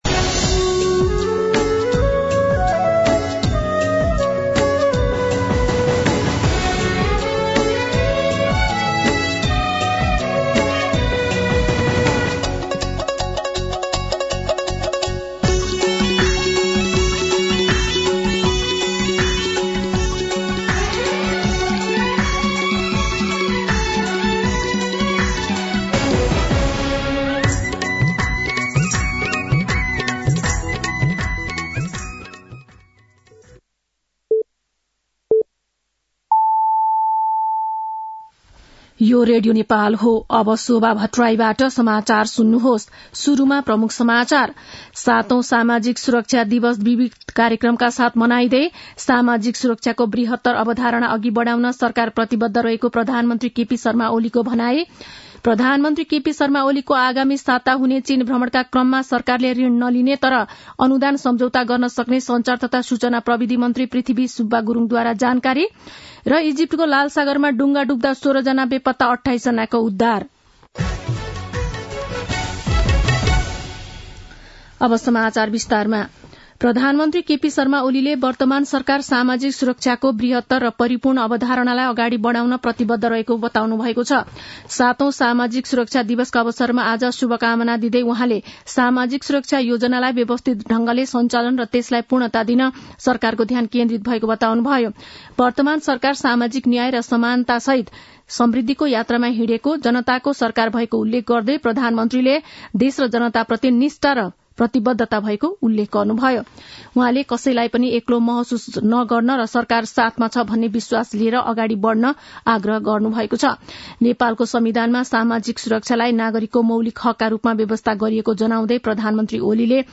दिउँसो ३ बजेको नेपाली समाचार : १२ मंसिर , २०८१
3pm-Nepali-news-.mp3